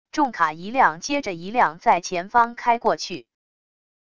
重卡一辆接着一辆在前方开过去wav音频